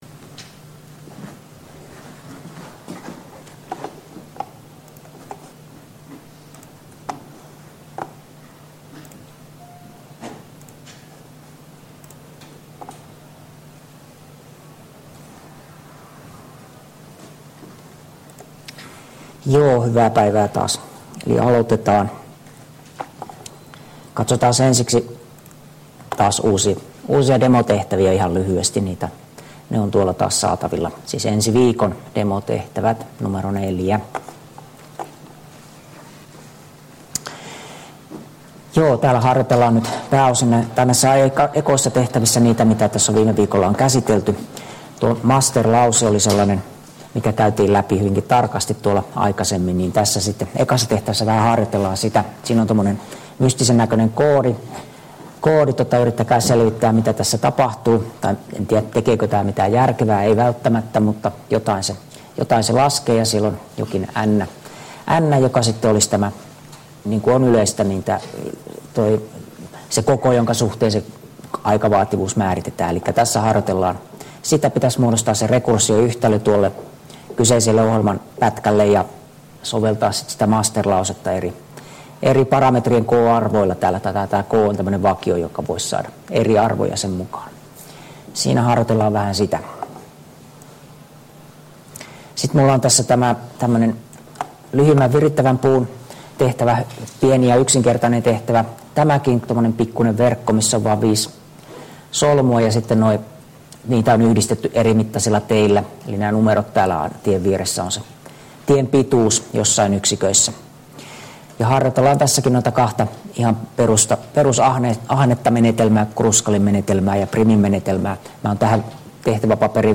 Luento 9 — Moniviestin